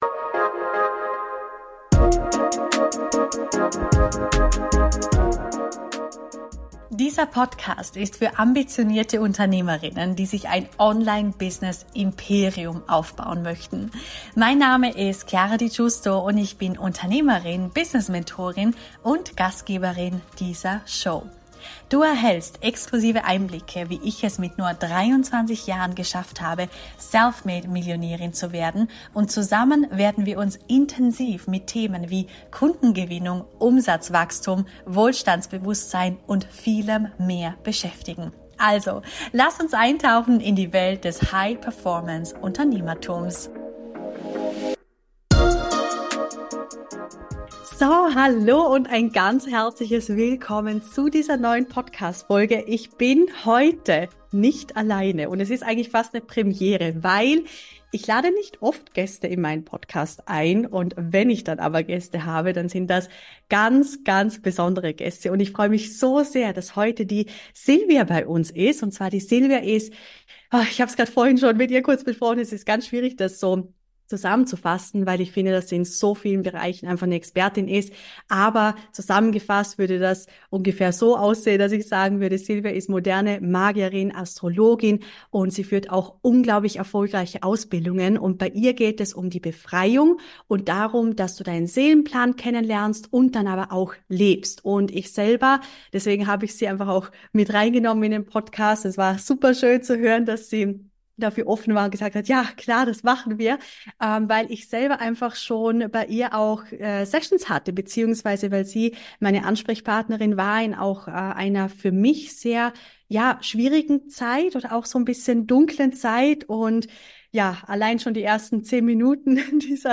#139 Transformation deiner Geld Realität in Lichtgeschwindigkeit - Interview